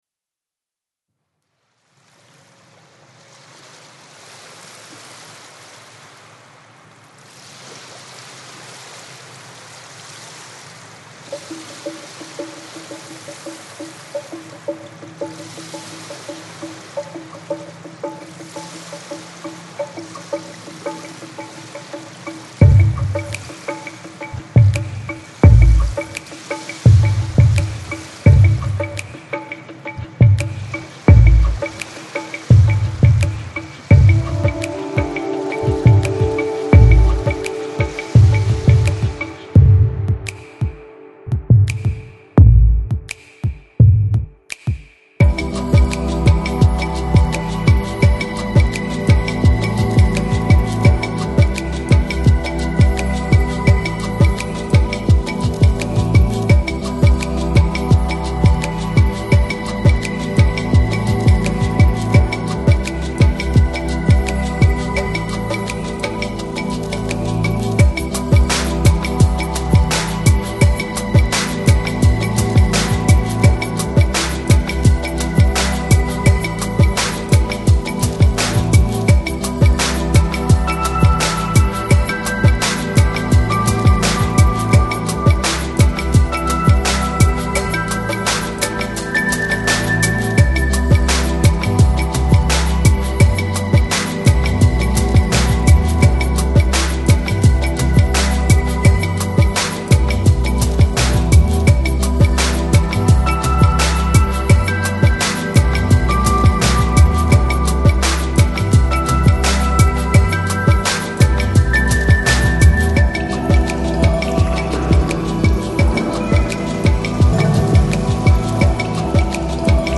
Жанр: Electronic, Downtempo, Ambient, Chill Out, Lounge